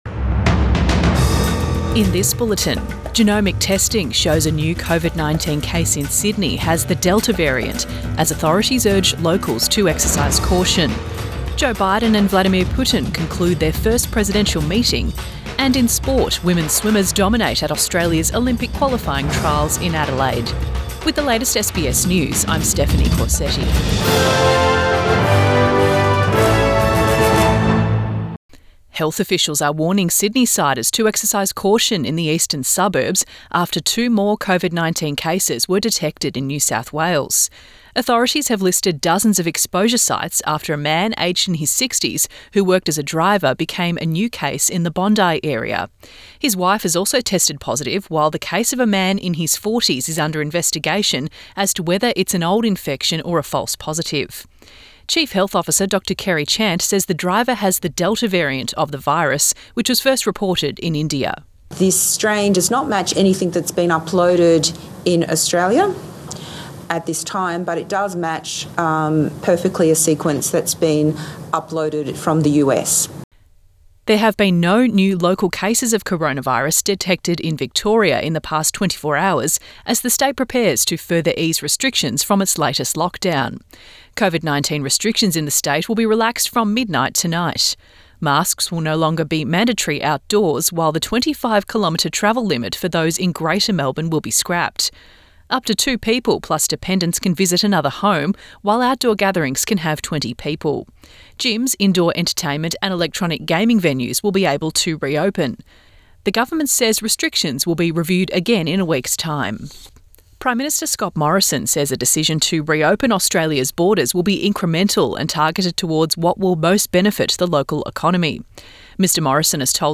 Midday bulletin 17 June 2021